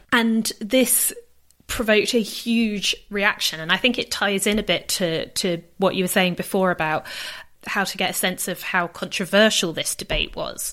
So much so that, as the following examples demonstrate, it also happens after vowels, which is more surprising and not very often described in the literature.